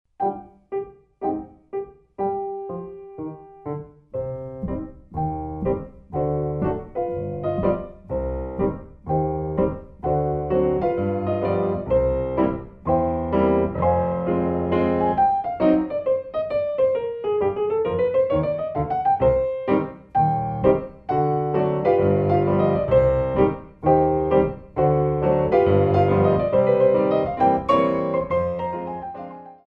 Degagés